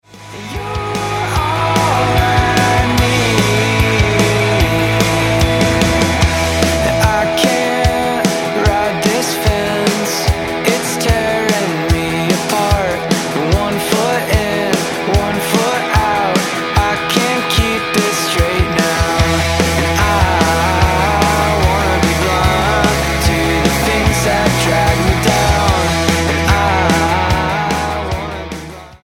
post-'90s indie rock band